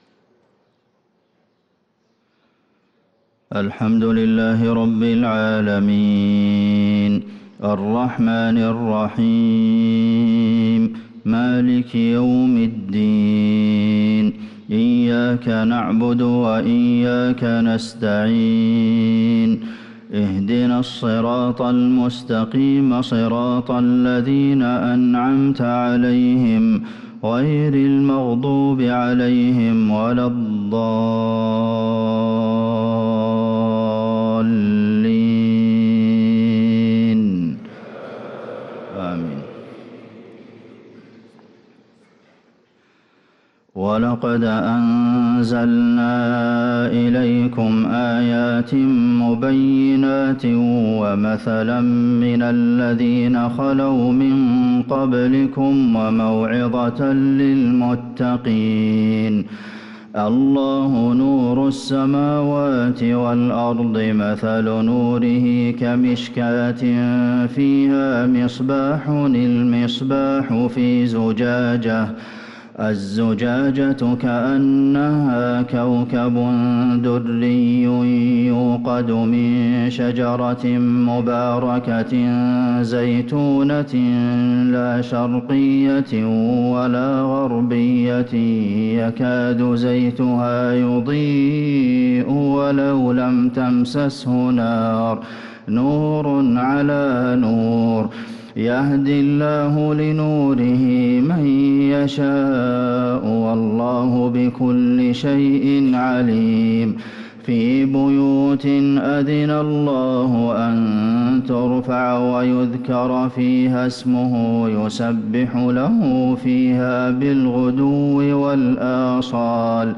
صلاة الفجر للقارئ عبدالمحسن القاسم 2 جمادي الأول 1445 هـ
تِلَاوَات الْحَرَمَيْن .